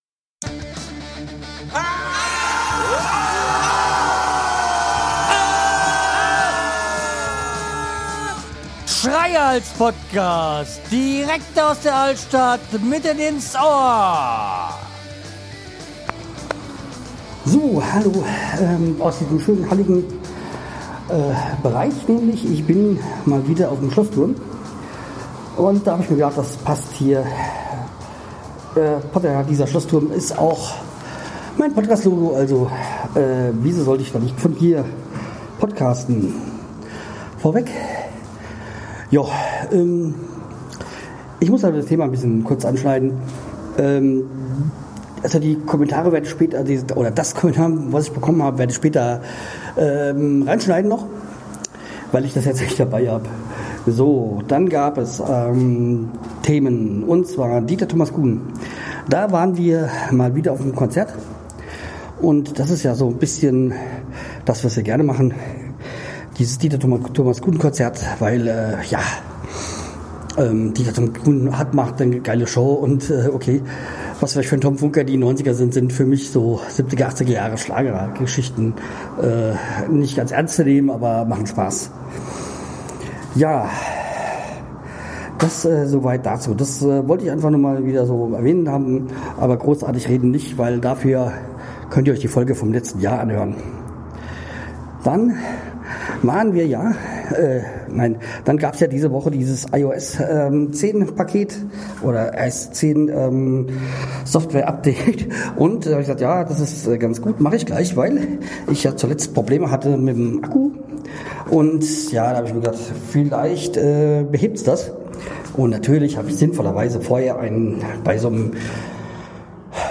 SP 375 Ich der Podcasthörer Schreihalzz Podcast Download In der Folge rede ich mal wieder mobil von unterwegs. Anfangs beginne ich über den Dächern Steinheims um mich dann geerdet über das Versprochene Thema Podcasts zu äussern.